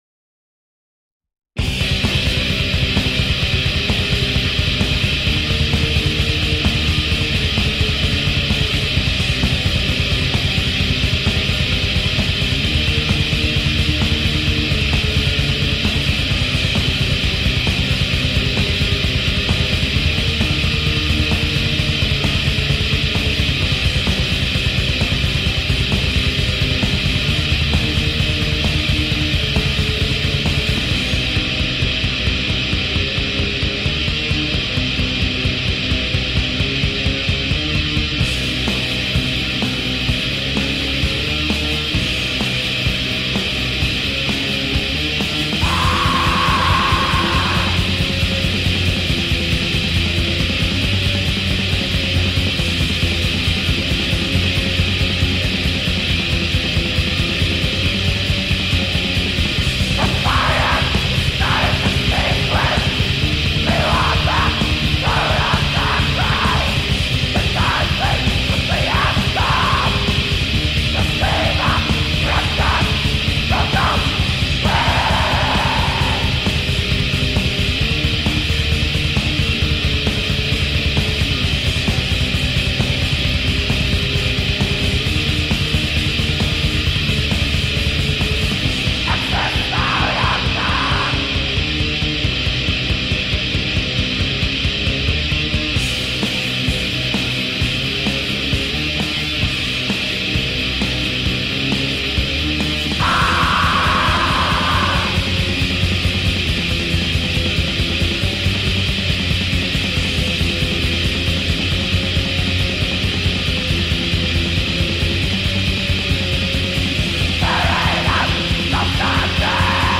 بلک متال